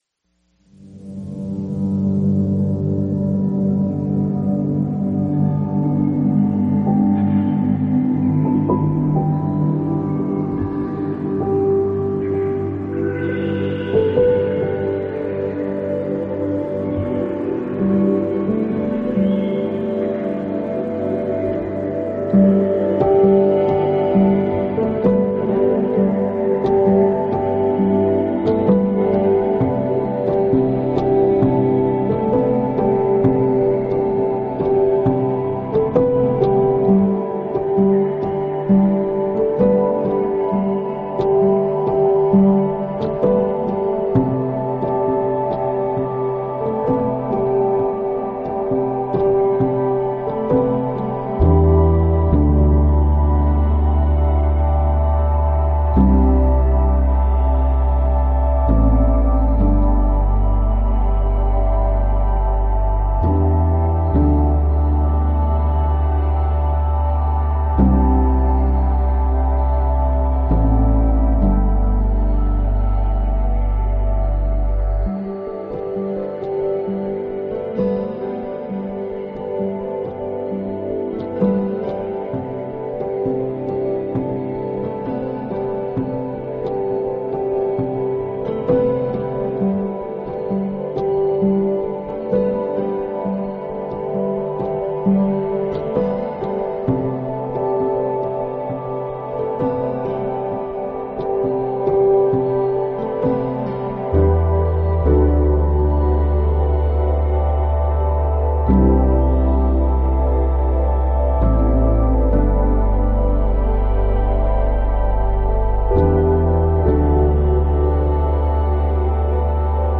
instrumental album